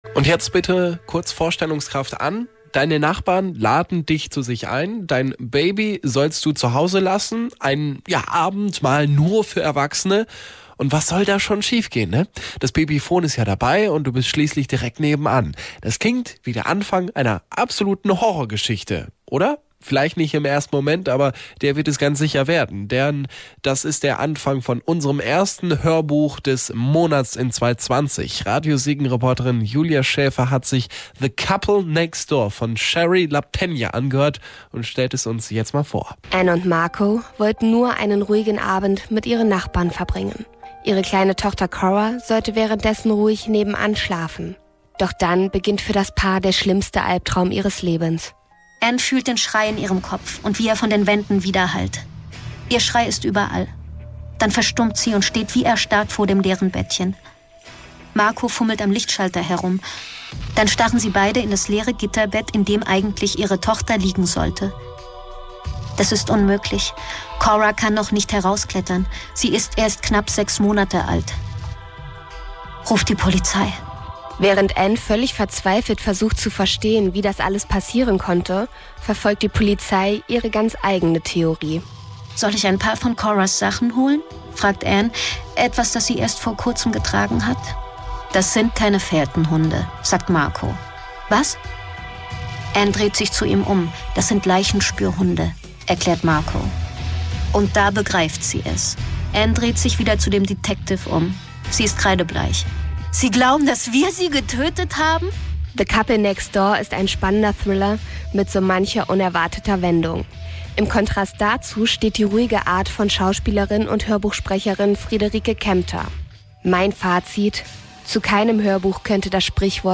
The Couple Next Door von Shari Lapena ist ein spannender psychologischer Thriller voller unerwarteter Wendungen. Sprecherin Friederike Kempter erzählt die emotionale Geschichte um ein entführtes Baby auf packend zurückhaltende und nüchterne Weise.